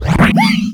CosmicRageSounds / ogg / general / combat / creatures / alien / he / hurt2.ogg
hurt2.ogg